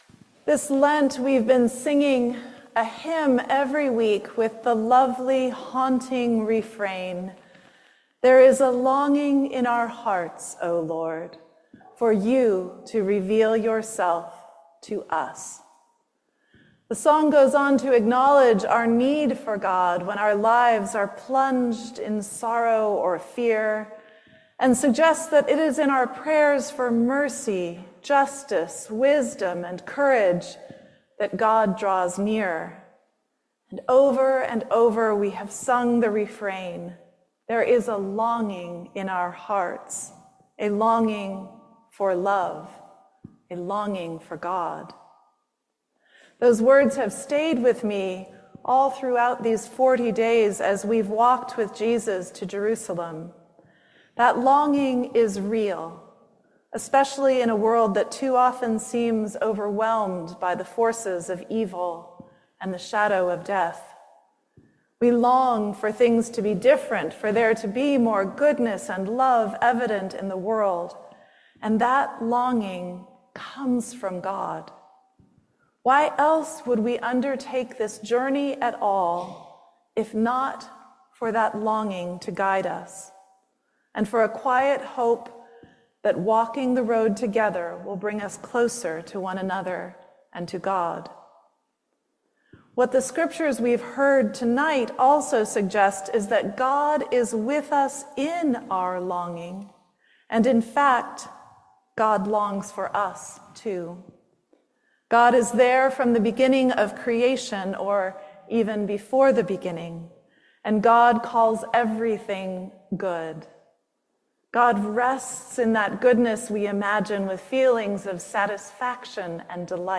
"Longing and Love": Sermon